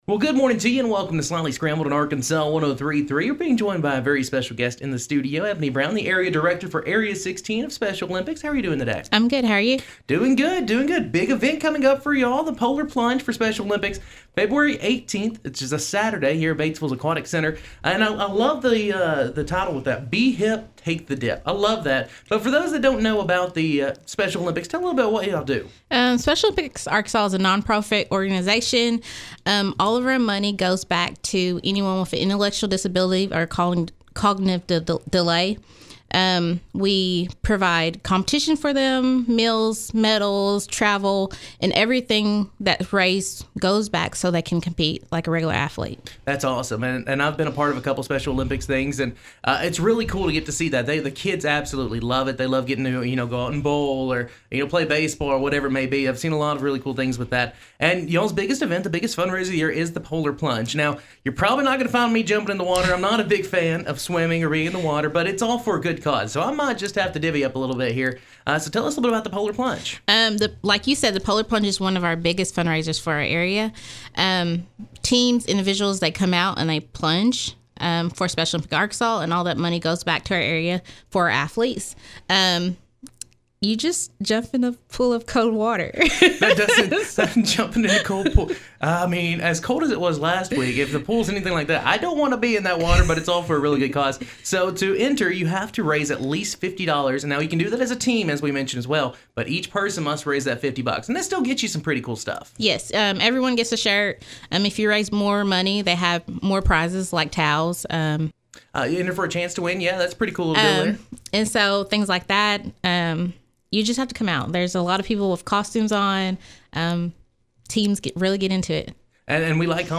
Polar-Plunge-Interview.mp3